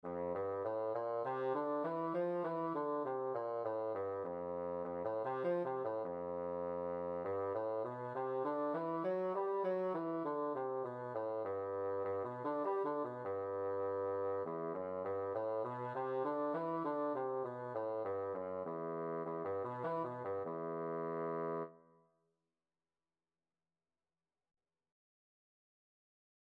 Bassoon scales and arpeggios - Grade 1
E3-G4
4/4 (View more 4/4 Music)
F major (Sounding Pitch) (View more F major Music for Bassoon )
bassoon_scales_grade1.mp3